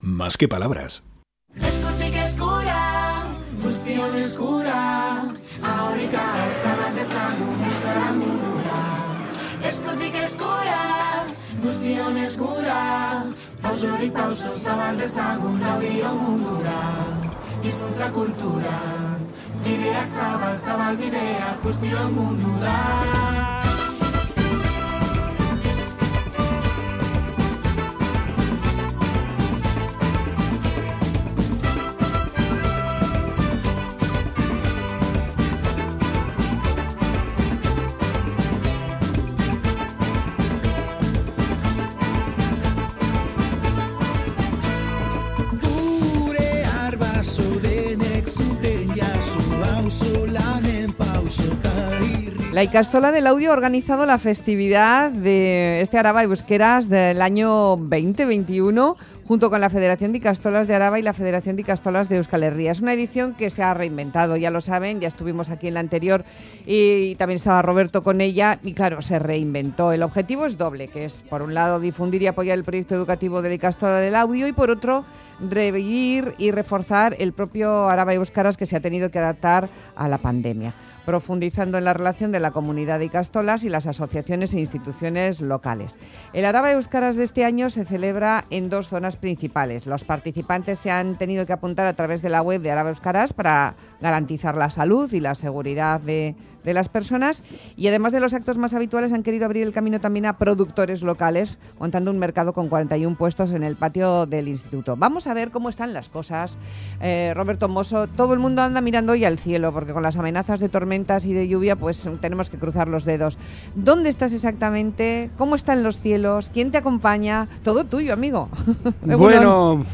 Audio: Desde Laudio, Araba Euskaraz 2021.